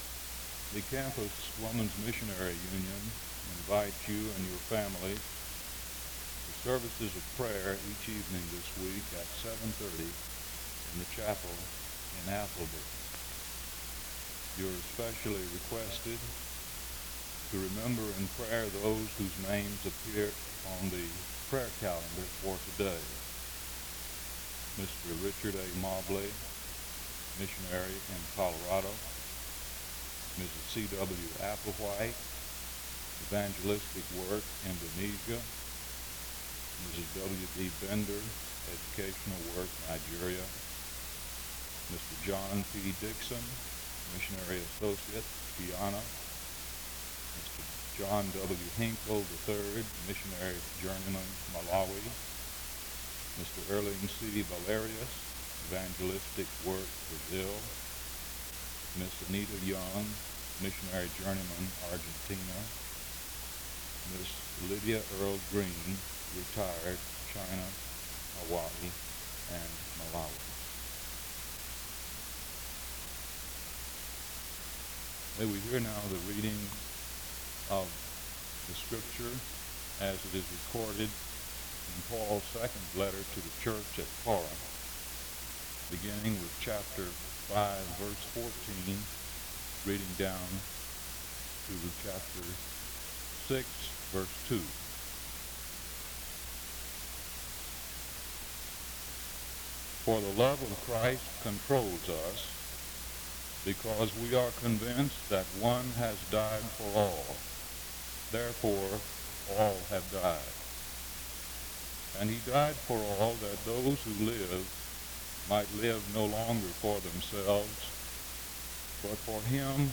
The service begins with an announcement on the prayer calendar for missionaries from 0:00-1:10. 2 Corinthians 5:14-6:2 is read from 1:14-3:52. A prayer is offered from 3:53-5:33. An introduction to the speaker is given from 5:41-8:18.